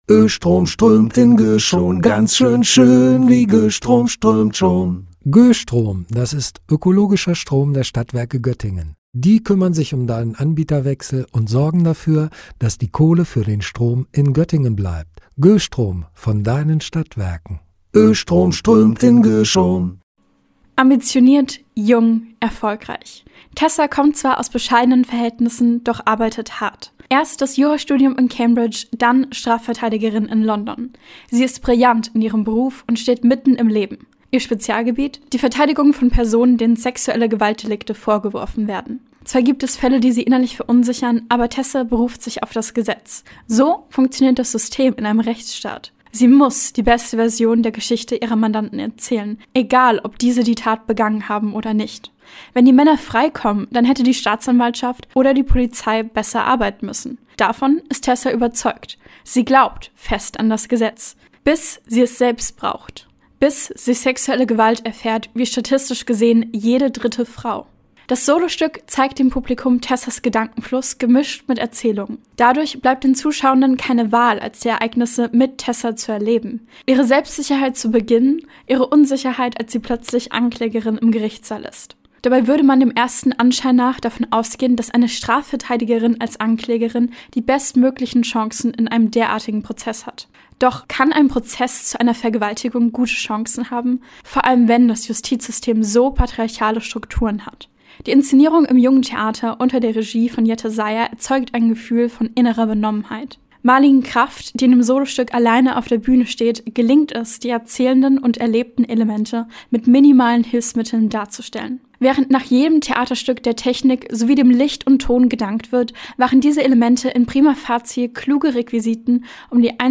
Beiträge > Rezension: "Prima Facie" im Jungen Theater - StadtRadio Göttingen